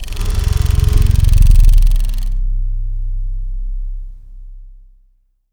rodextend.wav